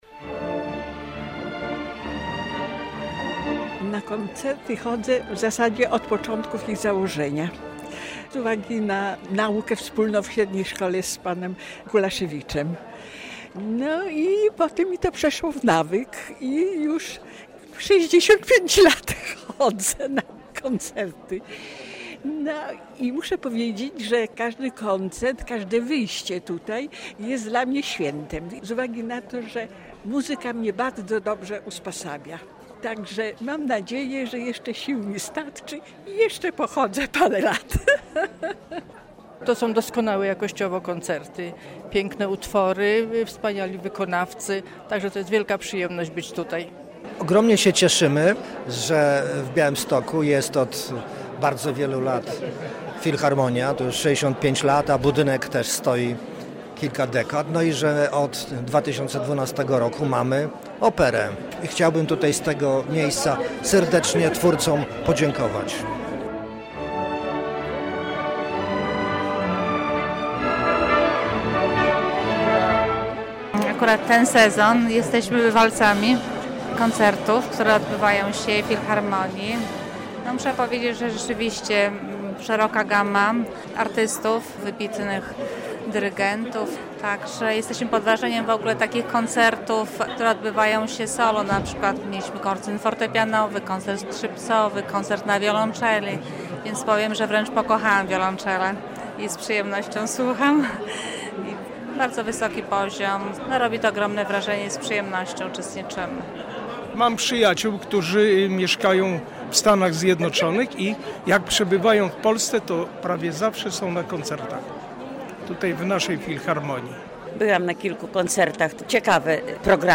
Pytaliśmy melomanów co dają im spotkania z muzyką klasyczną w wykonaniu białostockiej orkiestry - relacja